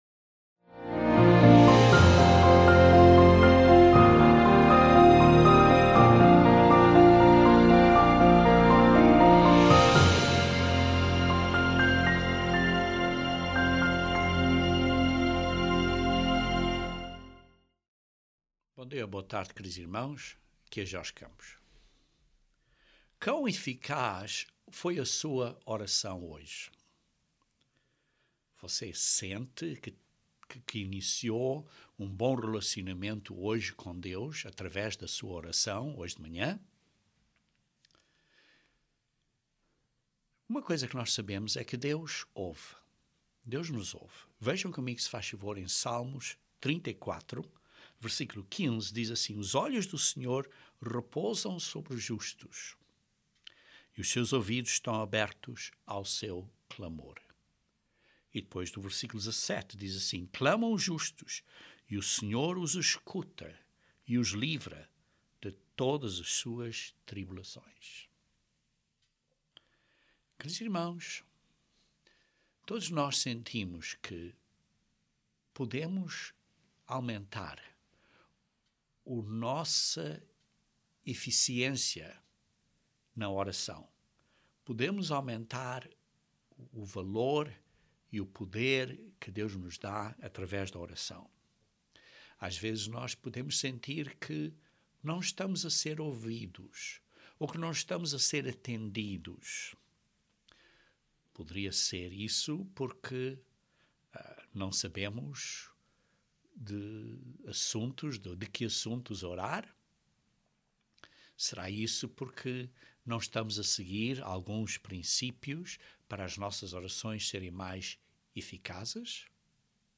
Este sermão descreve vários ensinamentos bíblicos de como e do quê que orar.